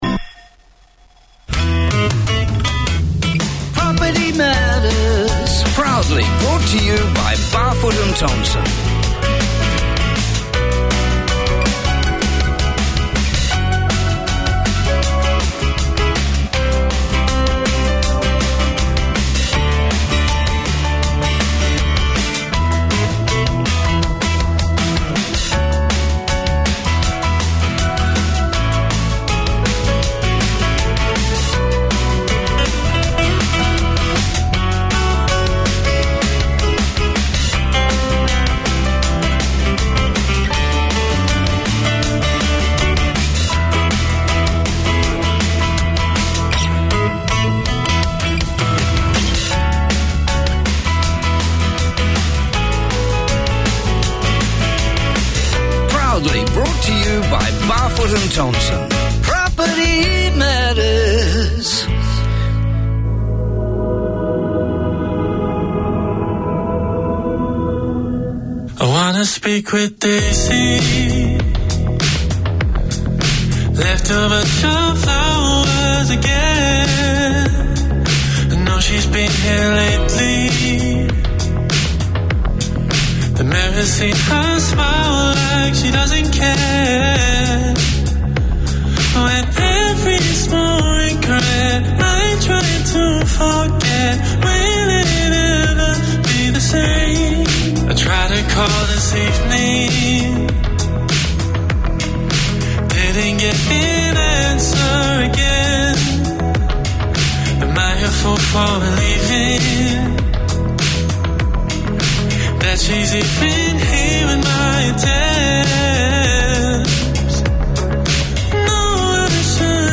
Presented by an experienced English teacher who understands the needs of his fellow migrants, these 30 minute lessons cover all aspects of English including Pronunciation, Speaking, Listening, Reading and Writing. Hear how to improve English for general and workplace communication, social interactions, job interviews, IELTS and academic writing, along with interviews with migrants and English experts.